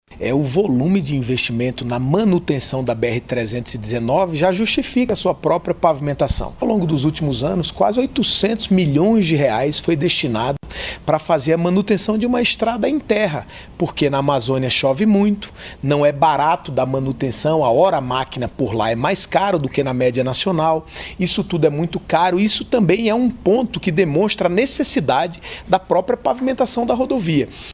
O ministro Renan Filho defende que a pavimentação completa da BR 319, porque, segundo ele, a rodovia asfaltada vai custar menos aos cofres do Governo, do que os investimentos na manutenção da estrada de barro. Ouça o que diz o Ministro:
Sonora-Renan-Calheiro-Filho-BR-319.mp3